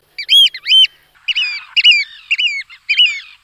Huitrier pie
Haematopus ostralegus
huitrier.mp3